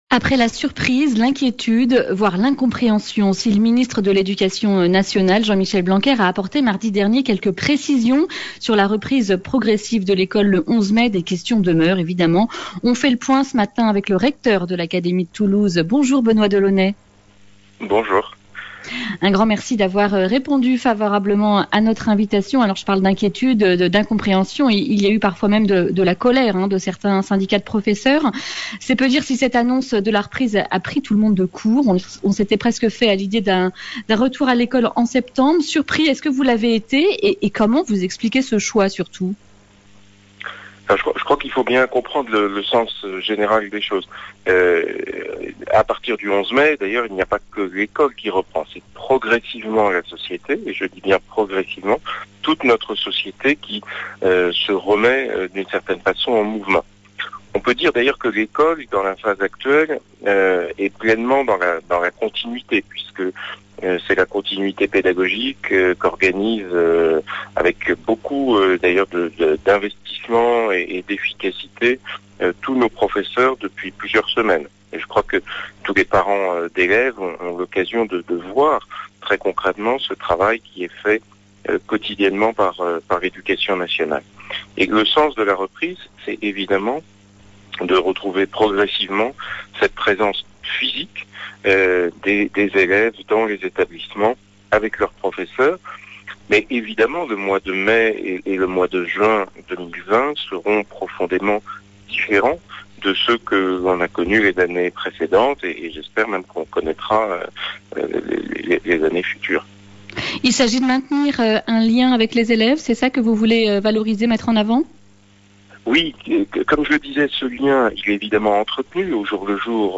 Le grand entretien